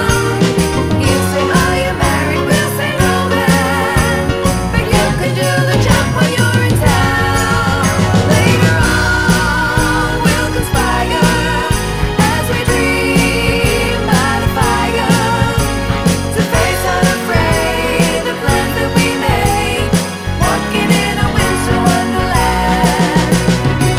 No Backing Vocals Christmas 2:14 Buy £1.50